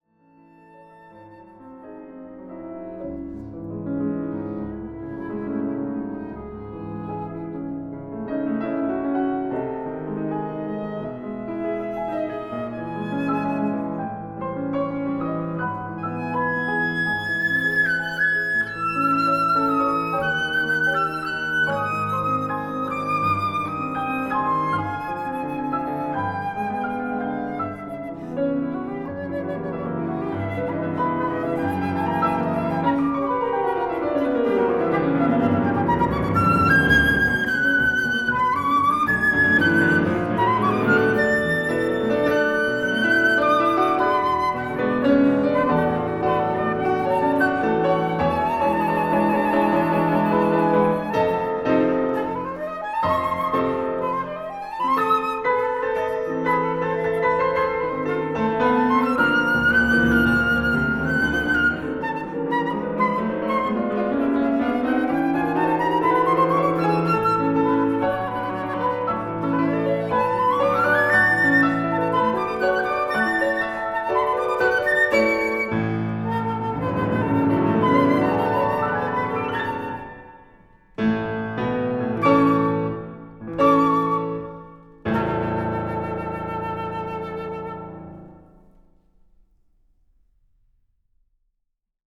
Werke für Flöte und Klavier